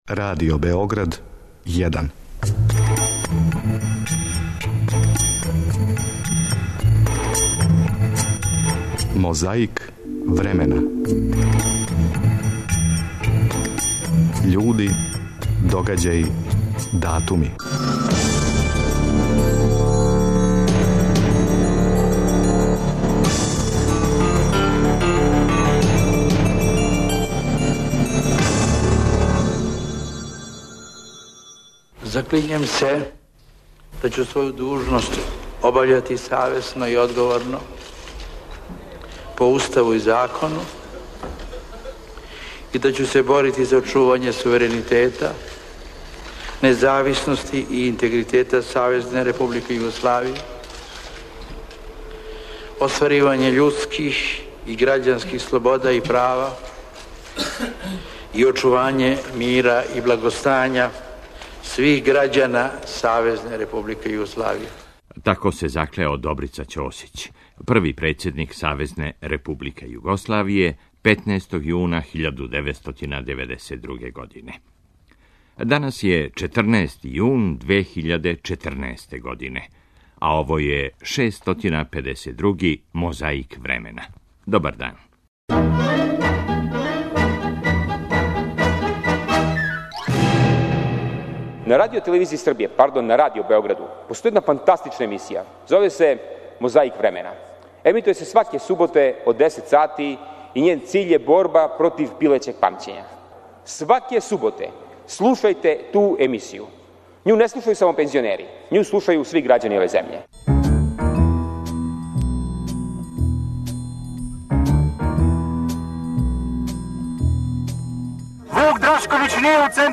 Користећи мегафон, присутнима се обратио Драган Веселинов.
Каже се још и "положио заклетву", након чега је и пригодно беседио.
Подсећа на прошлост (културну, историјску, политичку, спортску и сваку другу) уз помоћ материјала из Тонског архива, Документације и библиотеке Радио Београда.